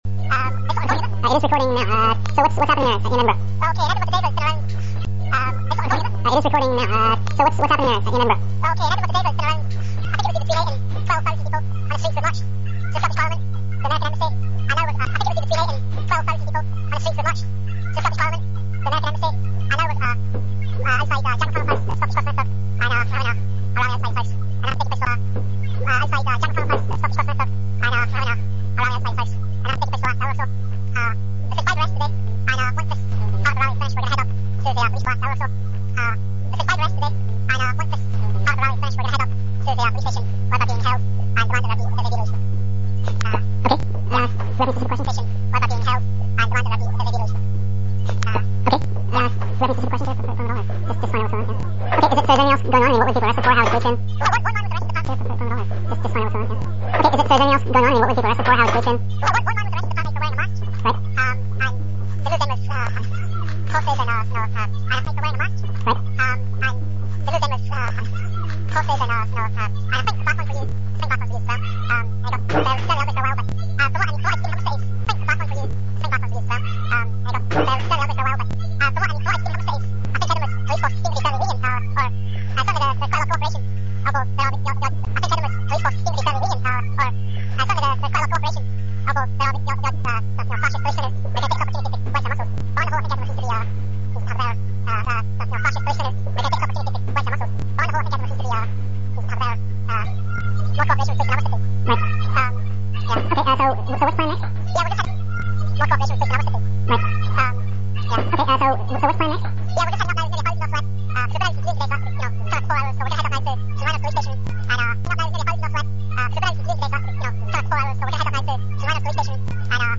Audio interview with demonstrators in Edinburgh, who are reporting 5 arrests. The main body of the demonstrators is now moving toward the police station to demand the release of those in jail.